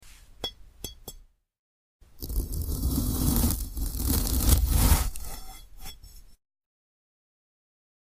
ASMR Slicing a Glass Strawberry